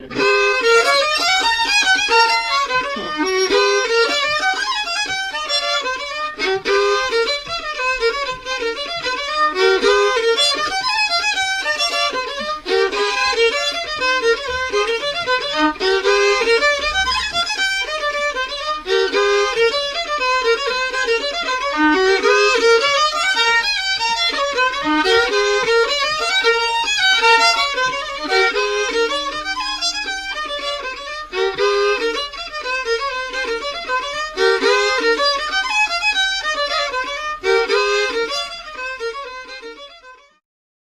6. Mazurek (1982 r.)
W tle słychać było głosy, jęki, nawoływania.
skrzypce
basy 3-strunowe